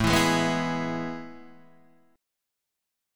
A Major 7th Suspended 2nd